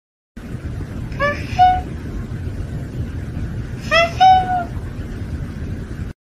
cat meows ha hee meme sound effects free download